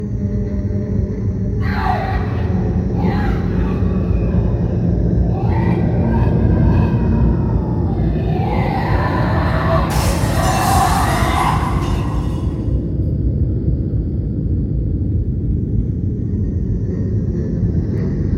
Ambiance Laboratoire du Donjon (Broadcast) – Le Studio JeeeP Prod
Bruits d’ambiance dans le laboratoire du donjon où sévit un monstre qui casse des vitres … entre autres.